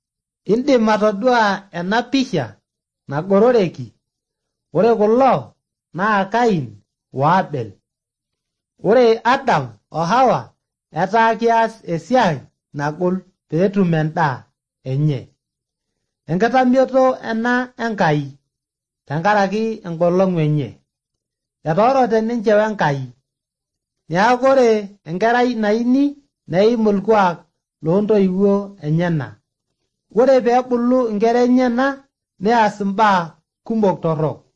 More consonants than last week, though.